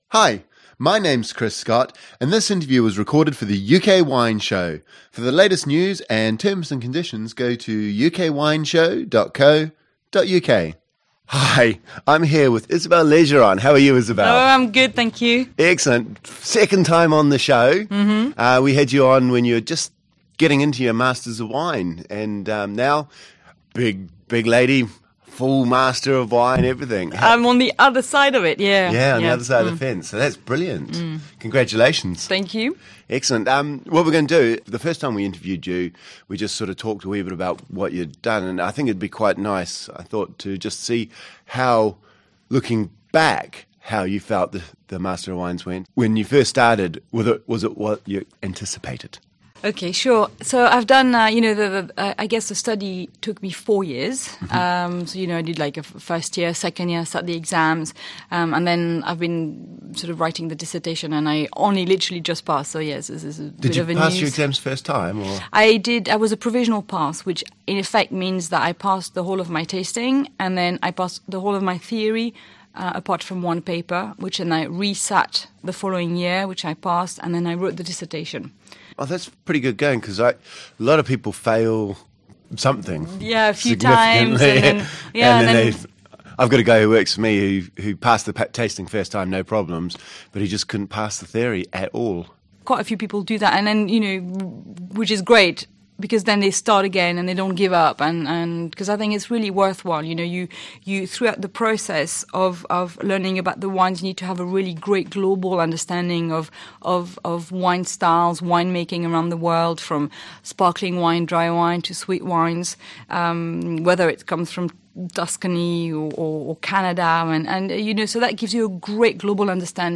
Listen to the full UK Wine Show